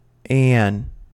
For most speakers, the short a sound /æ/ as in TRAP or BATH, which is not normally a tense vowel, is pronounced with tensing—the tongue raised, followed by a centering glide—whenever occurring before a nasal consonant (that is, before /m/, /n/ and, for many speakers, /ŋ/).[40] This sound may be broadly phonetically transcribed as [ɛə] (as in
En-us-Anne2.ogg.mp3